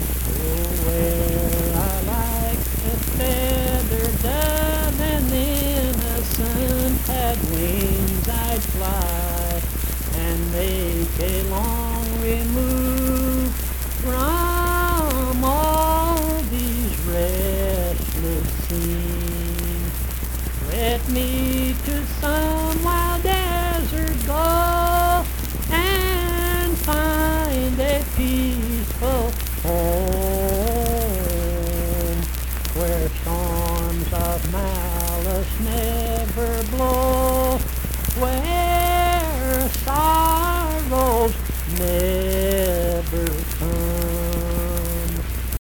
Accompanied (guitar) and unaccompanied vocal music
Verse-refrain 1(8). Performed in Mount Harmony, Marion County, WV.
Voice (sung)